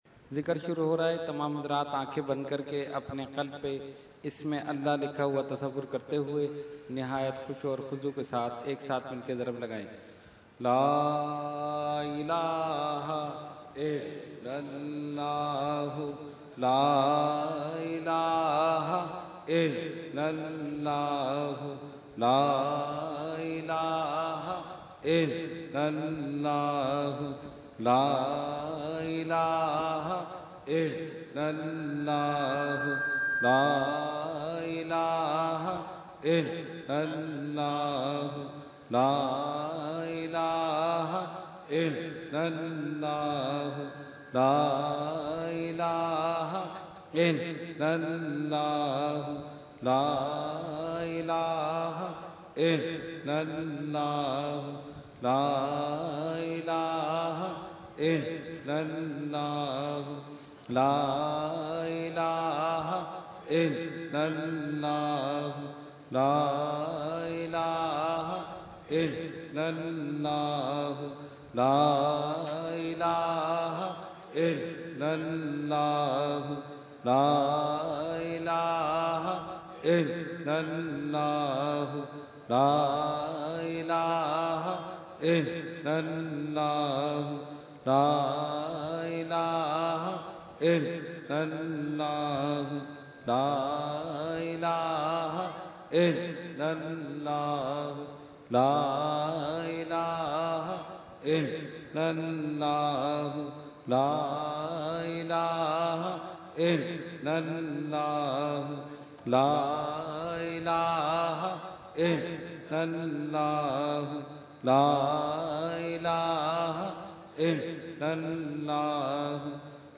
Zikar – Urs Makhdoome Samnani 2014 Day 3 – Dargah Alia Ashrafia Karachi Pakistan